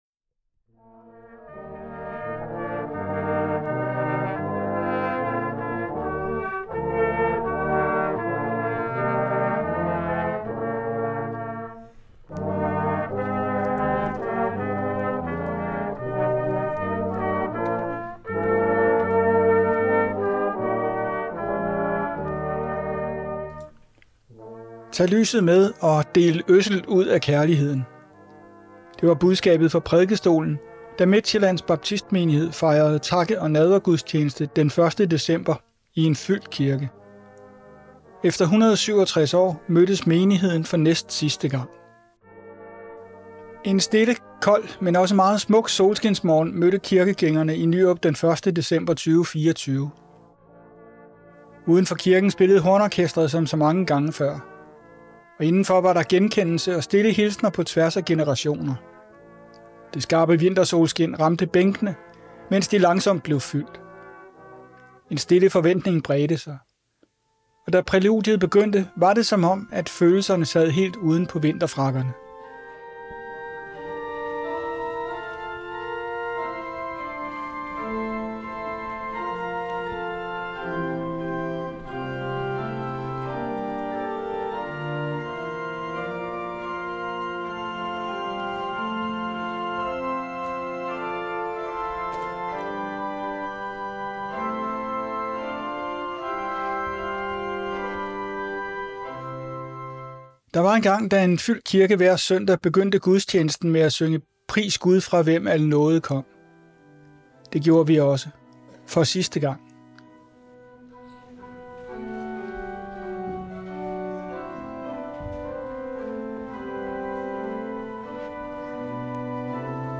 Midtsjællands Baptistmenighed lukker med udgangen af 2024 efter 167 år. Derfor var der takke- og nadvergudstjeneste i Nyrup den 1. december 2024.
Lyt til artiklen med lydklip fra dagen i Nyrup:
Udenfor kirken spillede hornorkestret som så mange gange før.